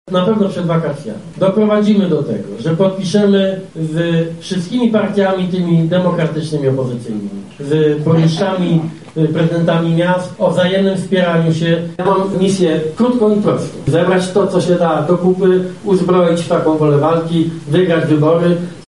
Szef Platformy Obywatelskiej Donald Tusk spotkał się ze zwolennikami w Lublinie.
-mówi  Lider Platformy Obywatelskiej Donald Tusk.